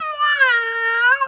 cat_2_meow_emote_03.wav